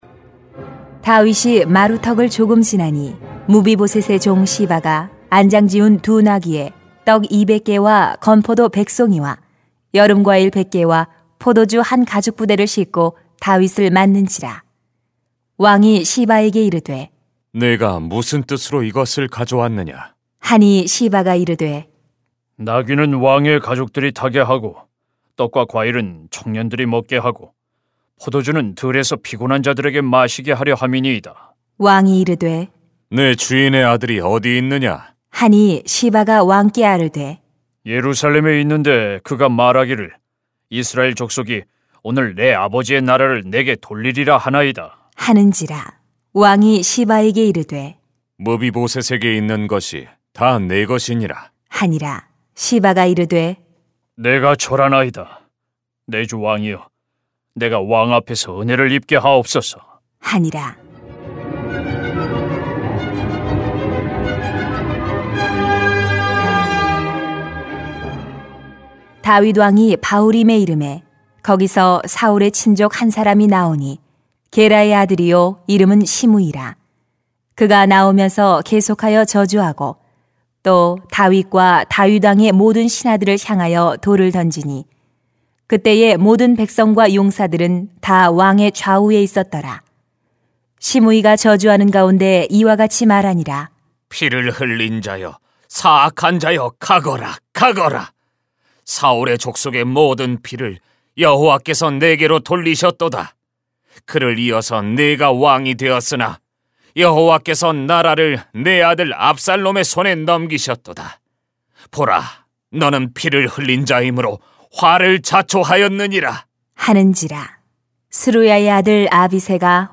[삼하 16:1-23] 양 극단에서 하나님을 생각하세요 > 새벽기도회 | 전주제자교회